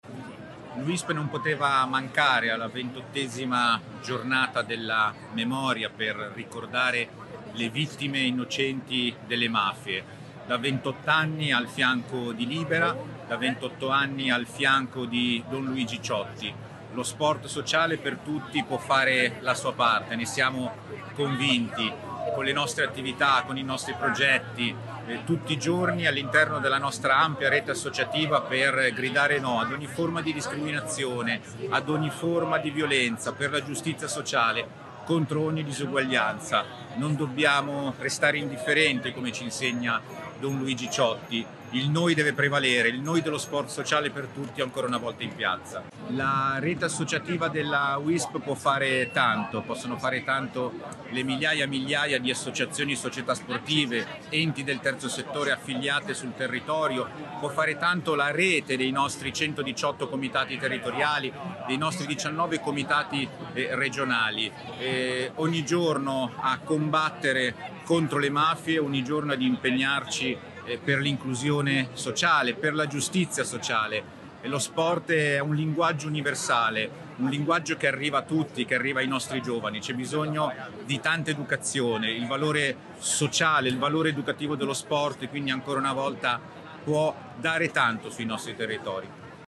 Abbiamo raccolto alcune voci e riflessioni provenienti dalla società civile, dalle associazioni, dalla politica
L’intervista